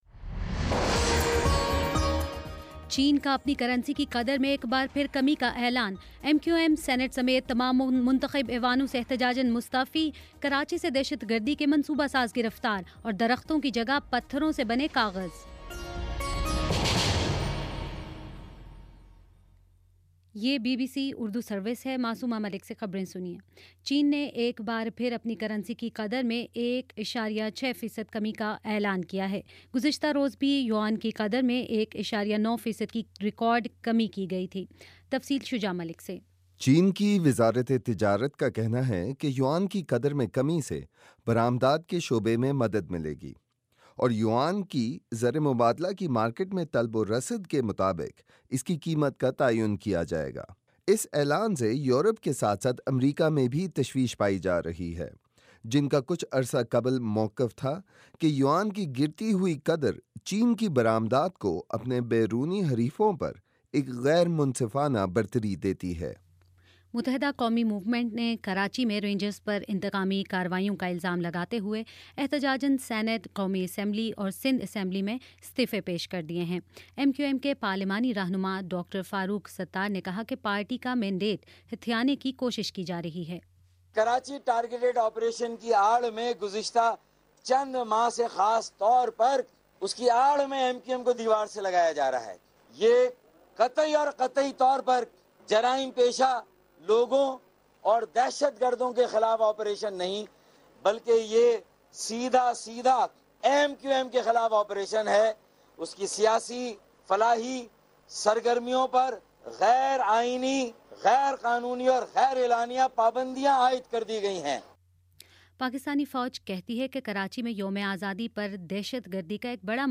اگست 12: شام سات بجے کا نیوز بُلیٹن